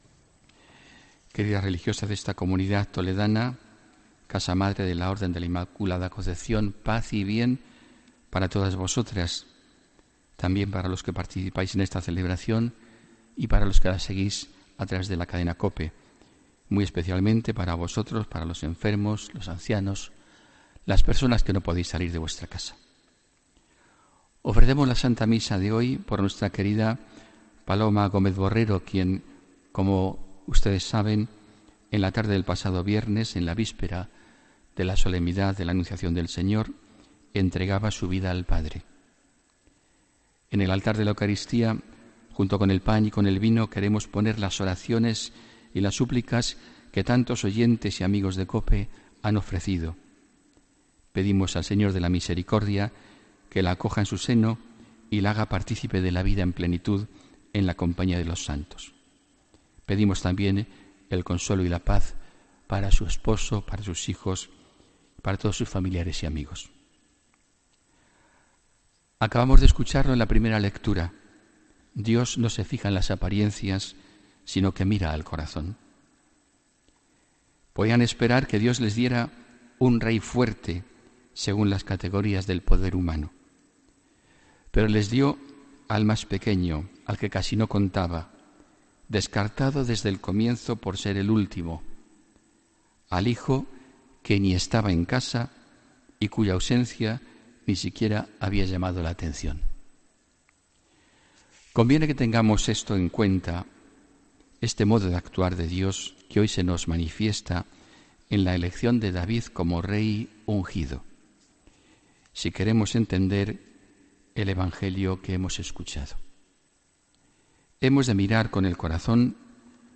Homilía del domingo 26 de marzo de 2017